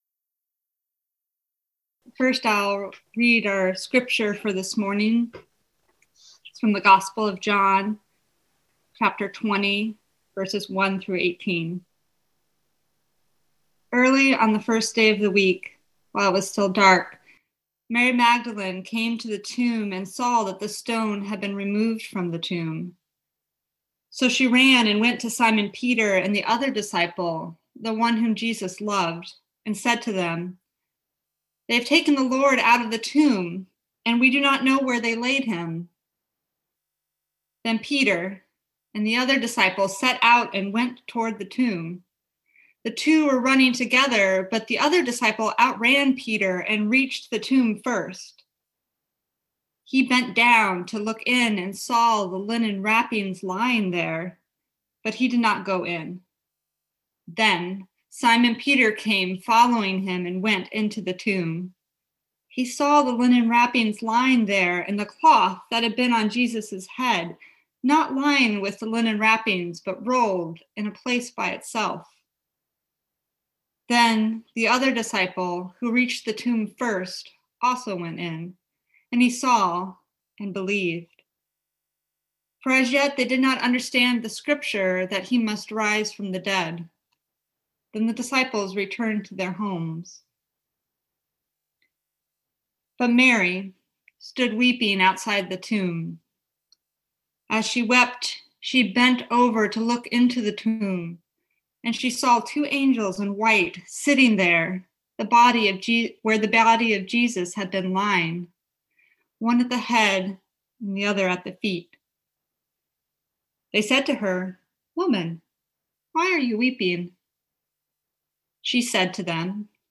Listen to the most recent message from Sunday worship at Berkeley Friends Church, “God for Us.”